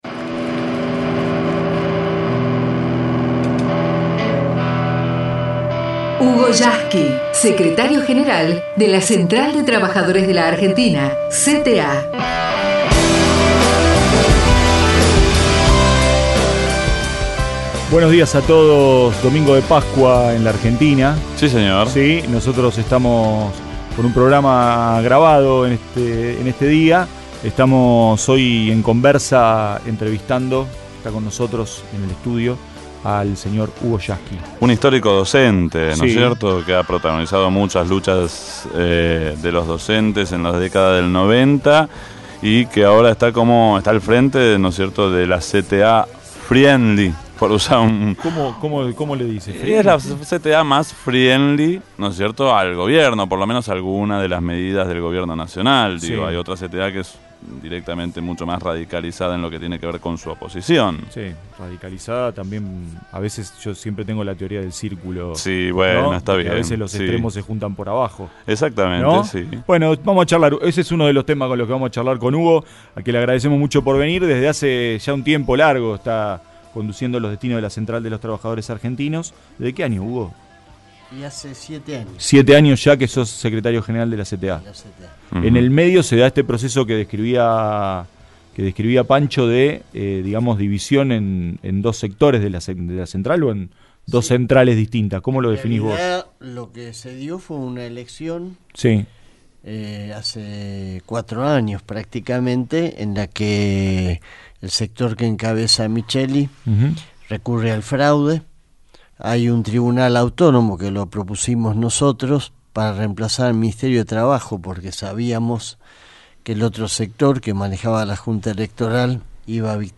El secretario General de la CTA entrevistado en el programa "Conversa" de la Radio Pública